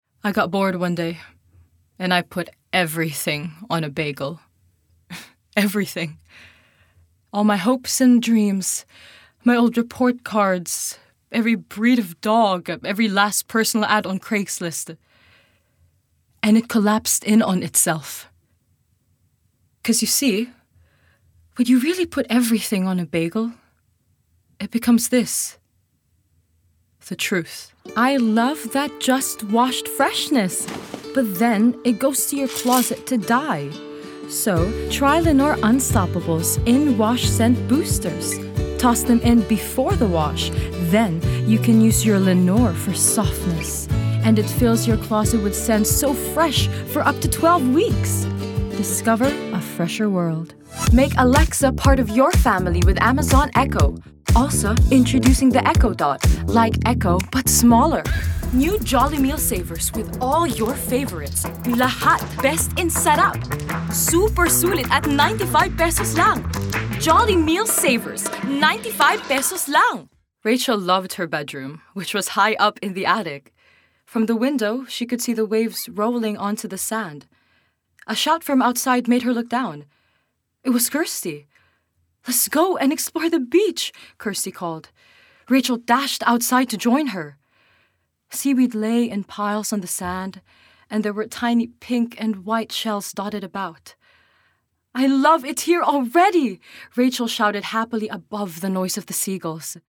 International English/Filipino
Voicereel:
BRITISH ISLES: Contemporary RP
GLOBAL: Standard-American, American-Southern States, New York, Filipino